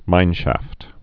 (mīnshăft)